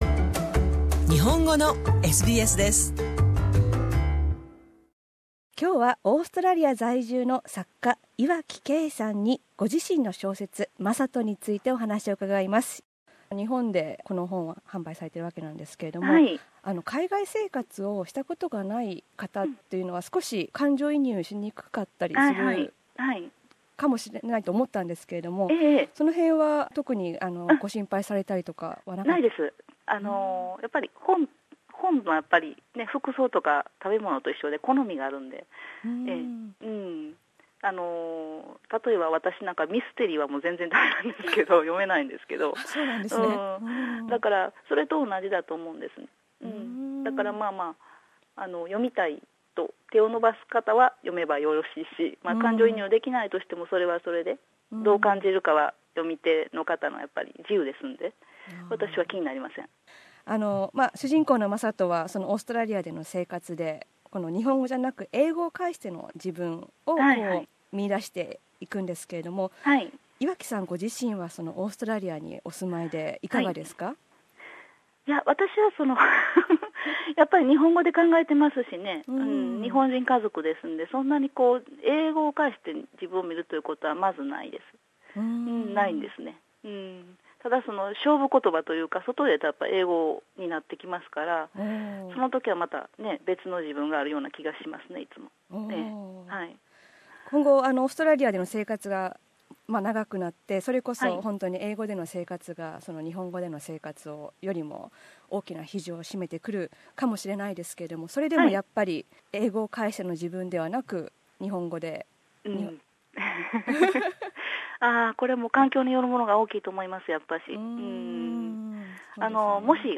パート2では、「言葉と人間」をテーマに作家活動をしている岩城さんに、「日本人らしさ」などについて聞きました。